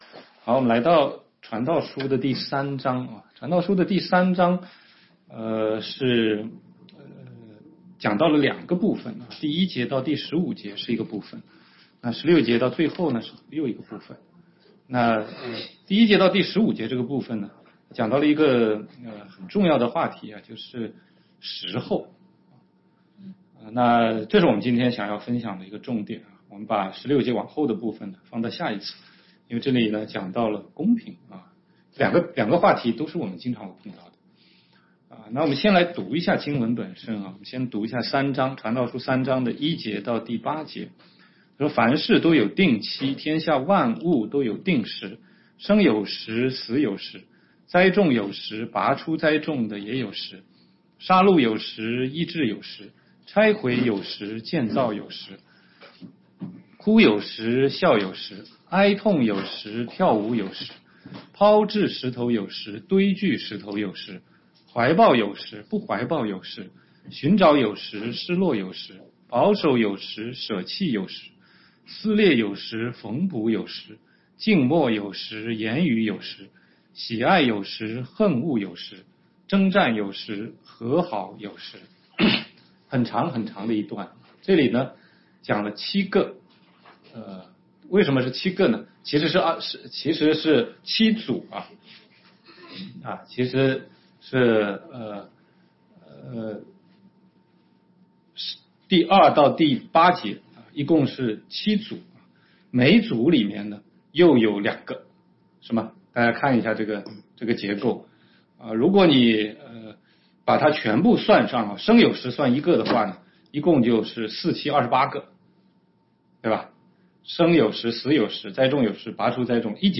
16街讲道录音 - 从传道书看人生的意义：从虚空到记念造你的主（3）